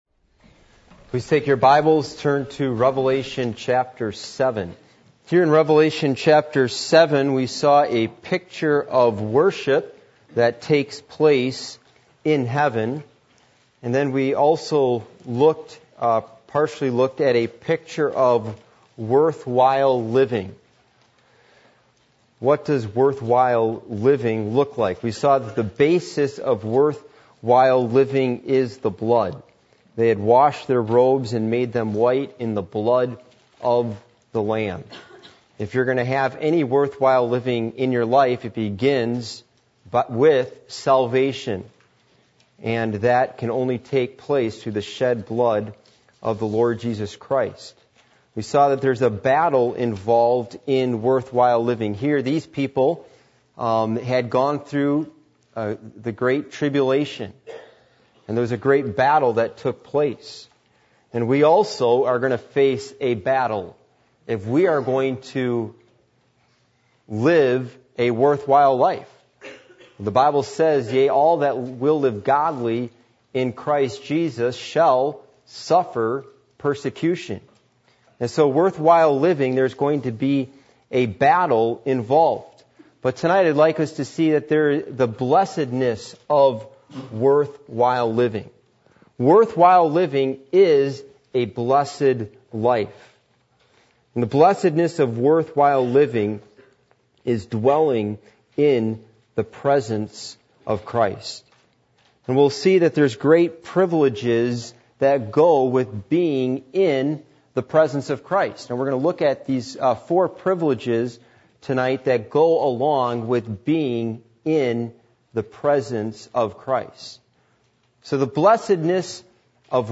Passage: Revelation 7:14-17 Service Type: Midweek Meeting